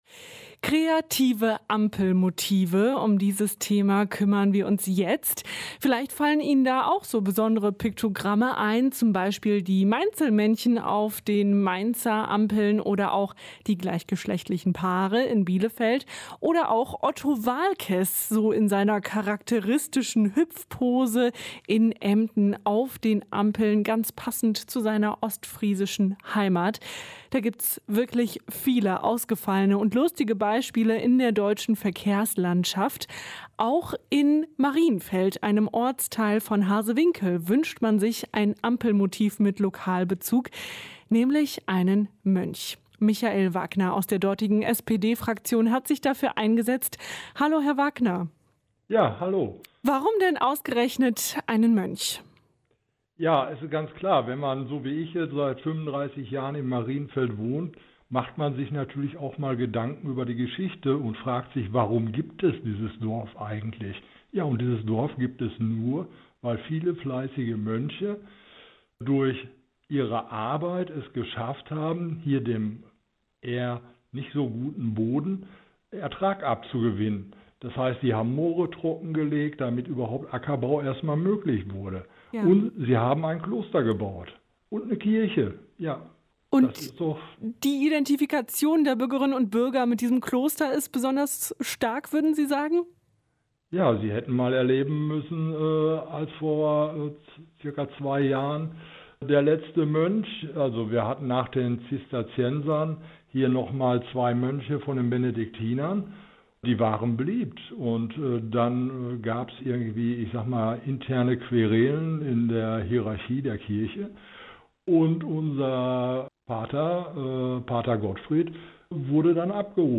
Ein Interview mit Michael Wagner (SPD-Ratsherr Harsewinkel)